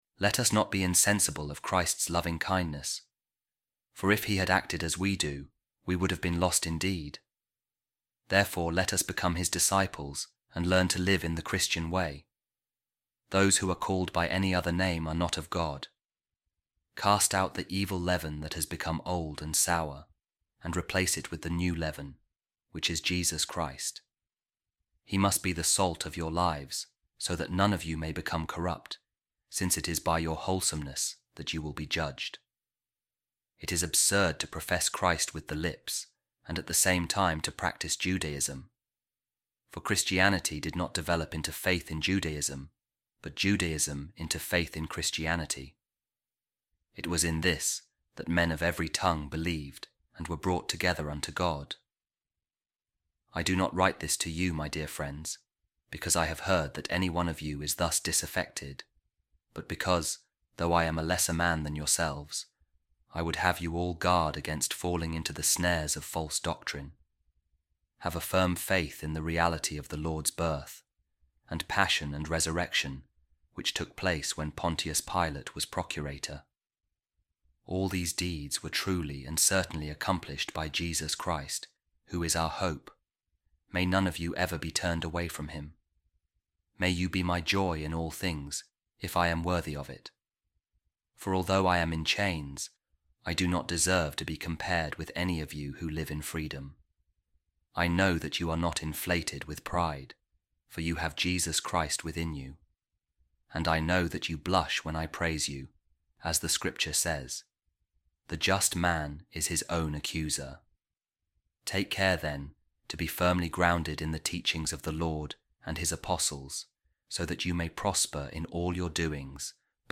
Office Of Readings | Week 16, Tuesday, Ordinary Time | A Reading From The Letter Of Saint Ignatius Of Antioch To The Magnesians | Jesus Christ Within You
office-readings-tuesday-16-saint-ignatius-antioch-magnesians.mp3